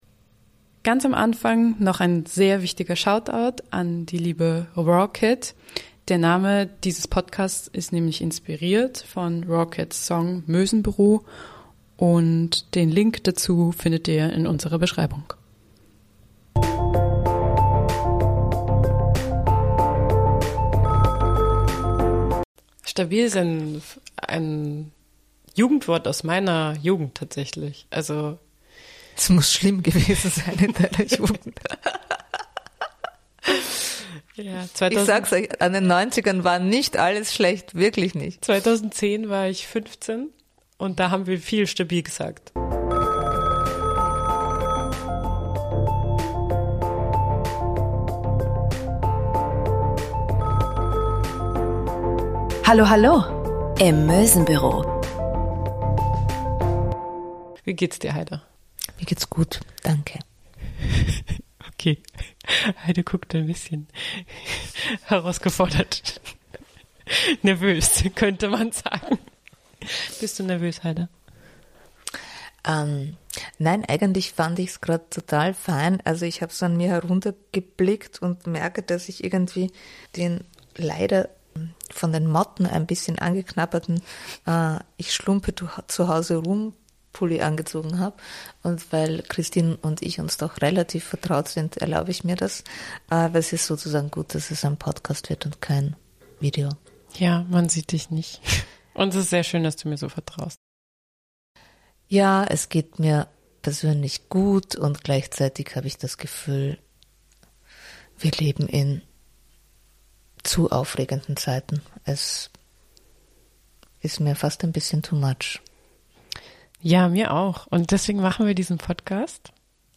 Da wir noch im "Trial and Error"-Modus sind, empfehlen wir für den vollen Genuss diese Folge über Lautsprecher oder mit zwei Kopfhörern zu hören.